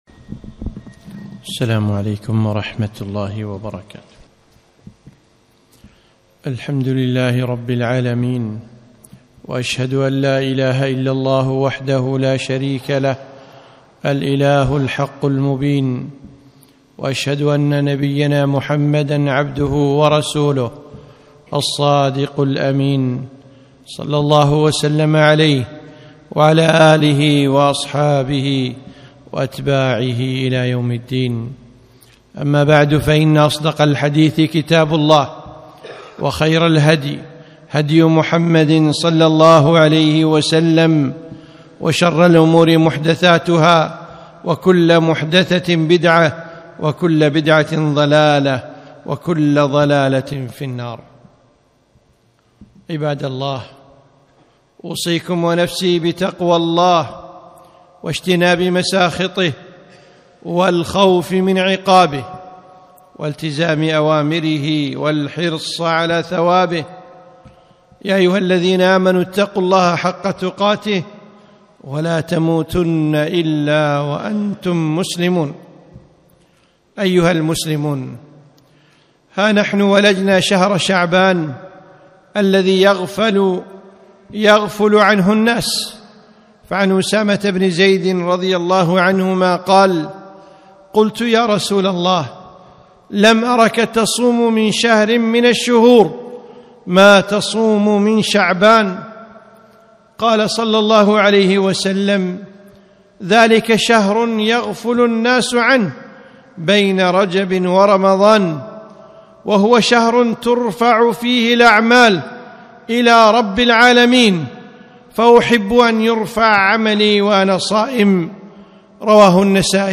خطبة - فضل شعبان